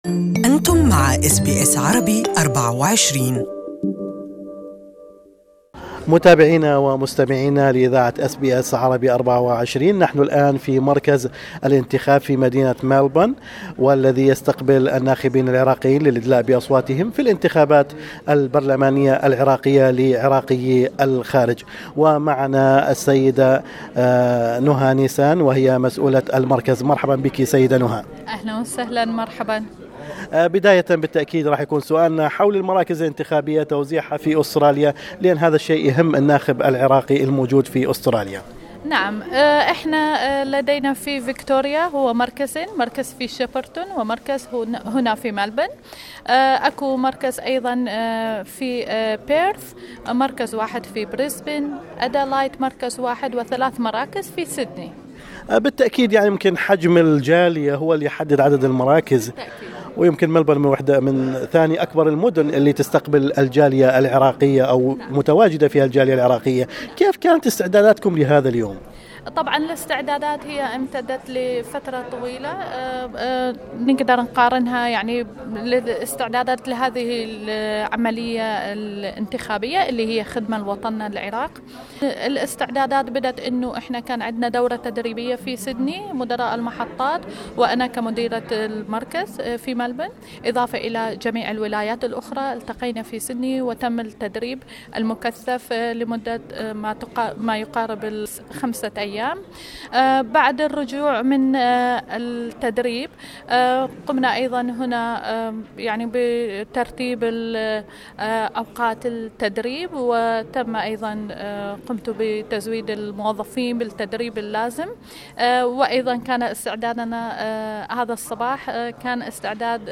مايكرفون SBS Arabic24 زار المركز الانتخابي في مدينة ملبورن واعد التغطية التالية